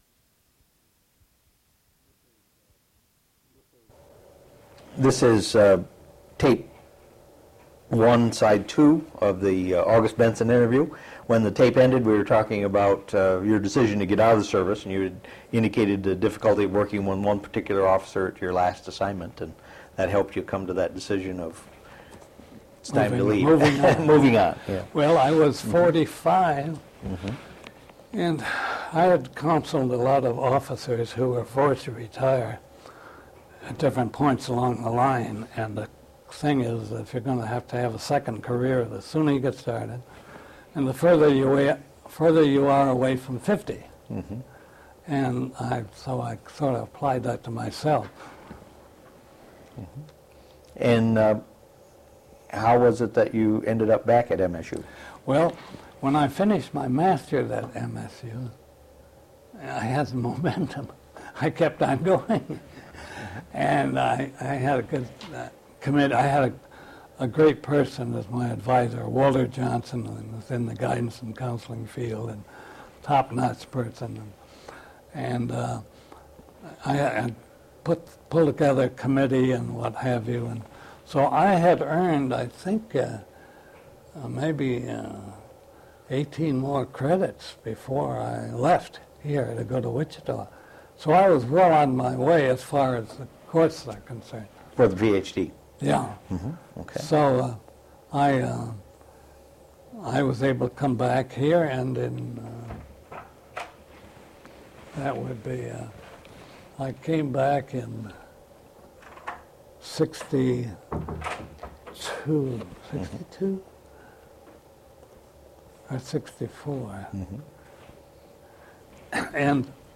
Original Format: Audiocassettes
Sesquicentennial Oral History Project